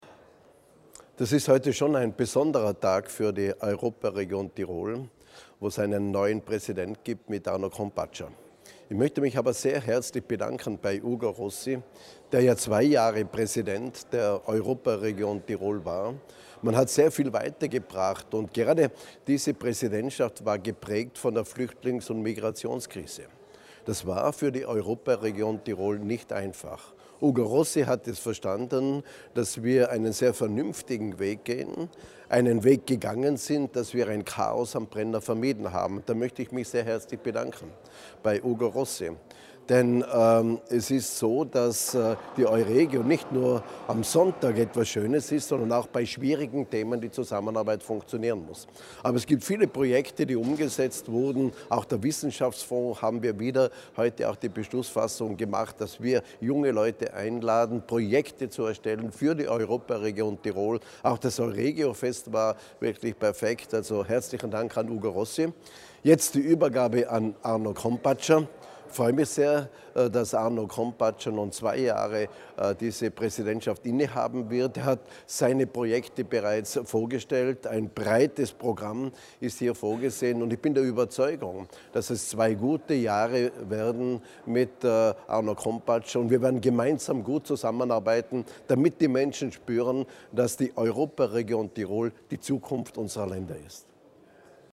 INTV_G+£NTHER_PLATTER_DE.mp3